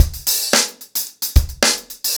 TupidCow-110BPM.61.wav